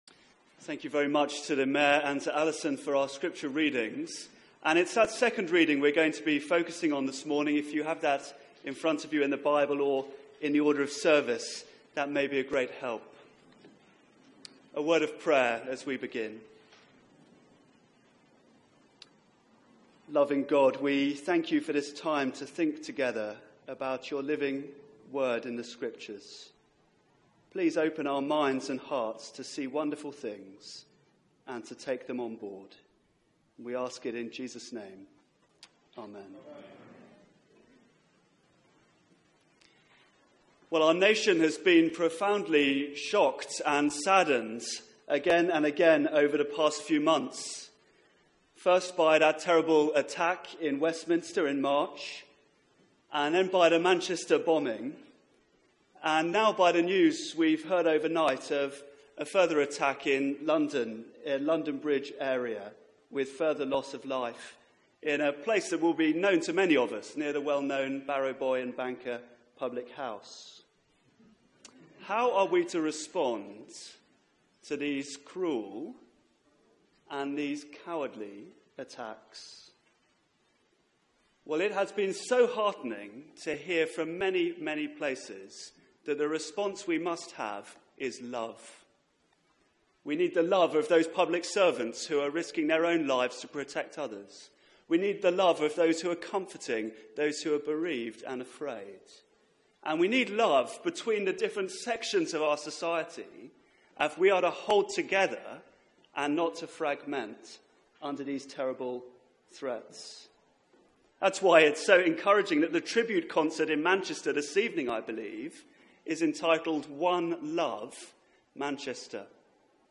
Media for 9:15am Service on Sun 04th Jun 2017 09:15
Theme: Real Christian Love Sermon